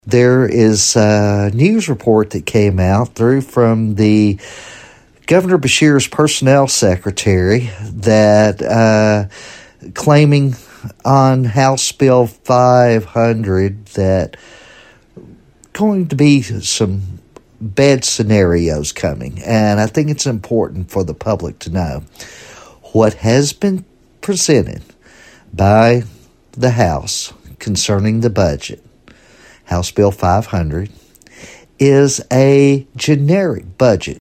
Ninth District State Representative Myron Dossett, who serves on the House Appropriations and Revenue Committee, says there is some misinformation being circulated about the budget bill that has been filed.